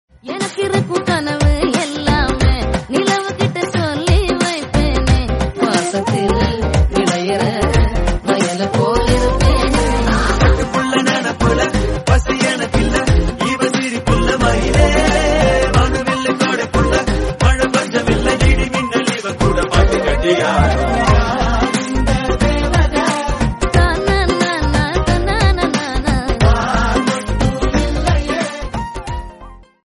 best flute ringtone download | love song ringtone